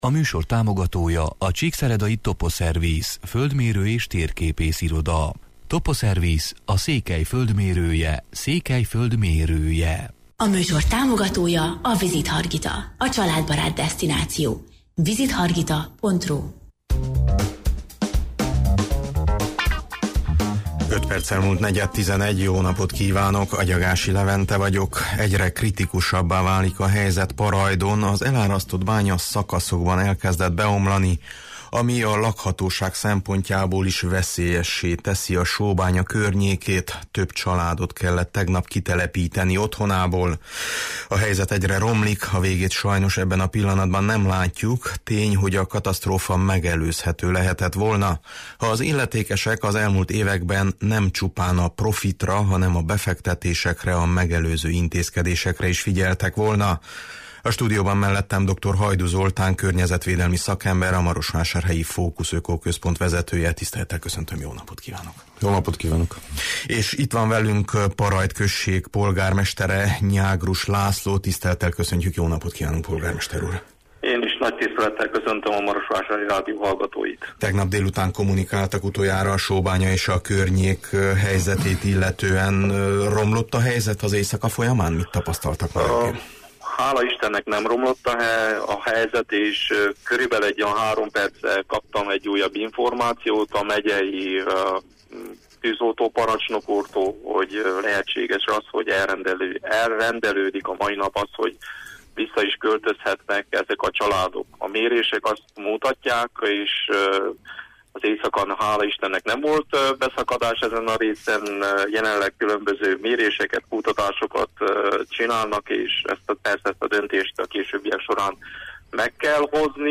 A studióban